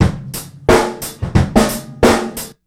Break Fave 1.wav